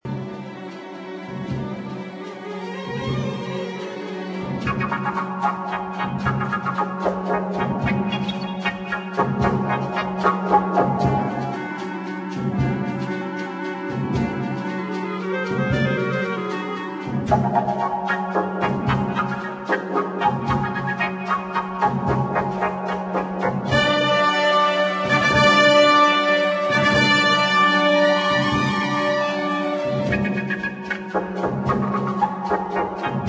1962 thrilling medium instr.